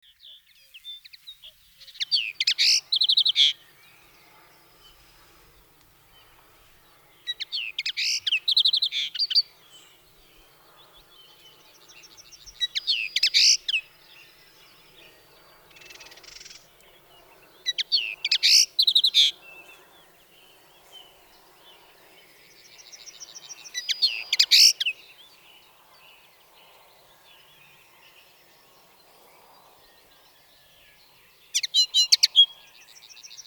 KFDn5ae8b4g_Linotte-mélodieuse-a.mp3